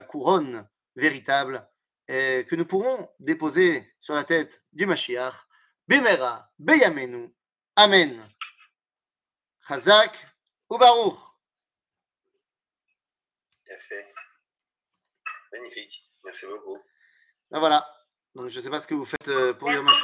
קטגוריה Mon Yom AShoa 00:47:41 Mon Yom AShoa שיעור מ 25 אפריל 2022 47MIN הורדה בקובץ אודיו MP3